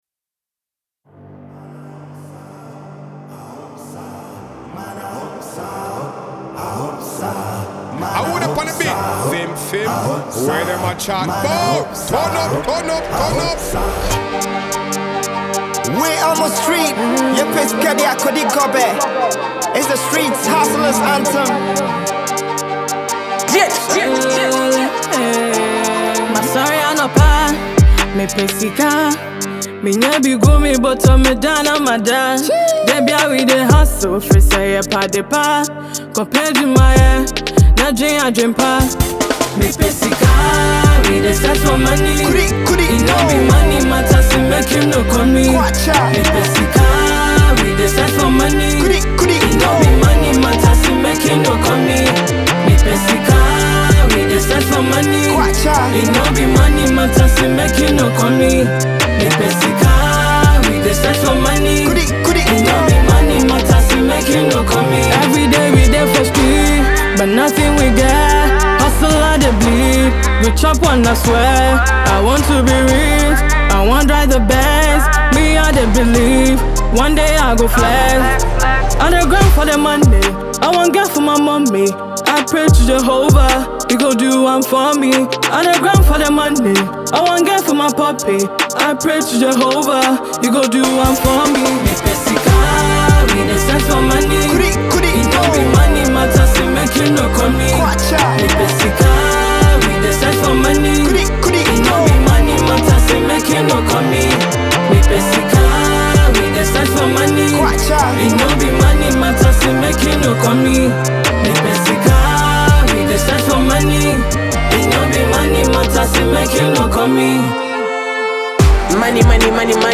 rapper
Highlife, Afrobeats, and Dancehall